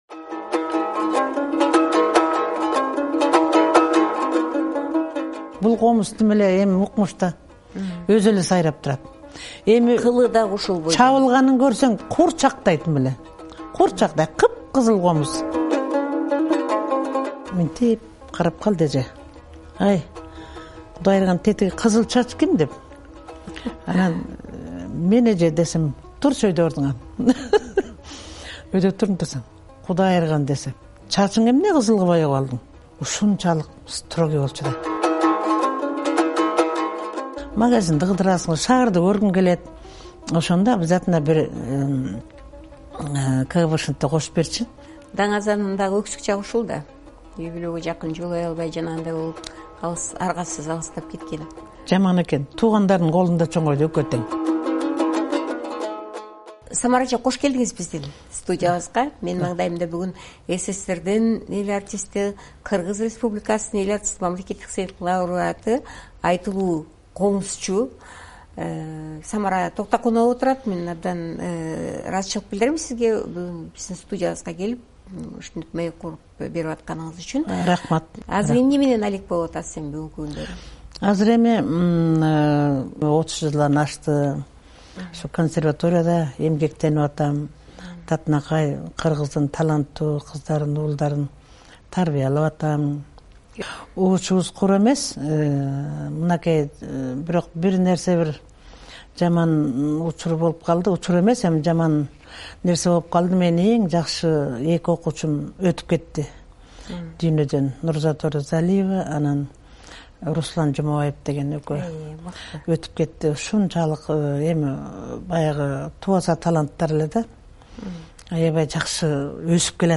"Сыябан" подкастынын коногу - Белгилүү комузчу Самара Токтакунова.